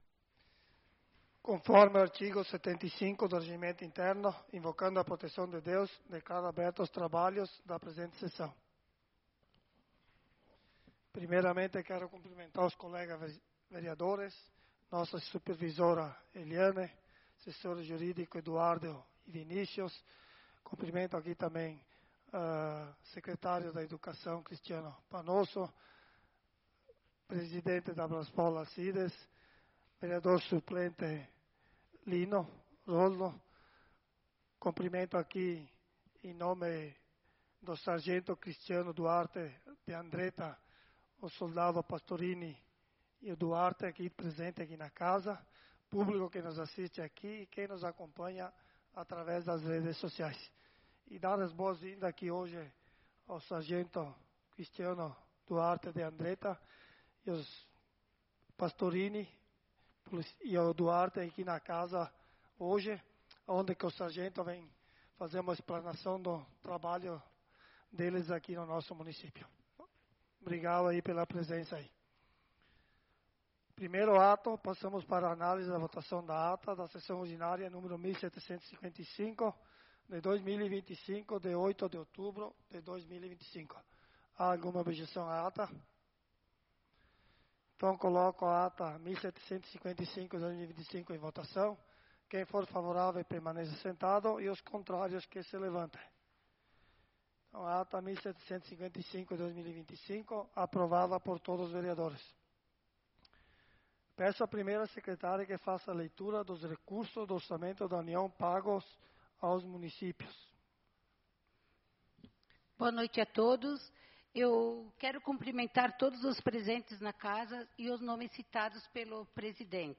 Sessão Ordinária do dia 15/10/2025